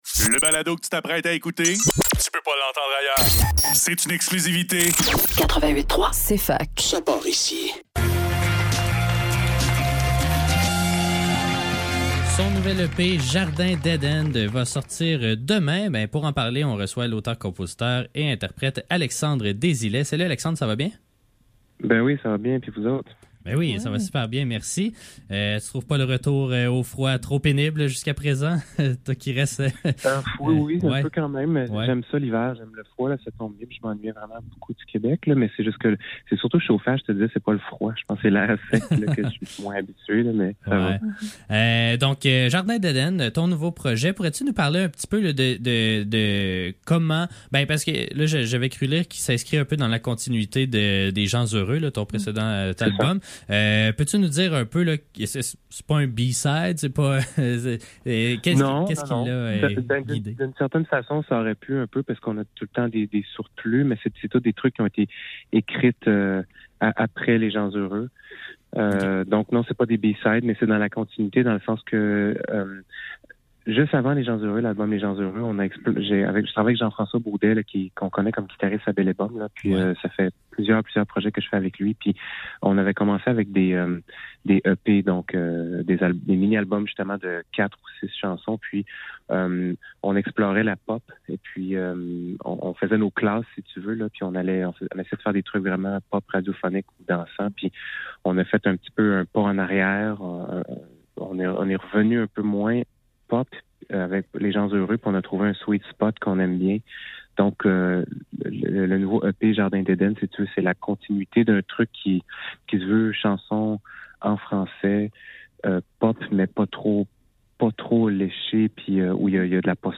Faudrait que tout l'monde en parle - Entrevue avec Alexandre Désilets - 25 janvier 2024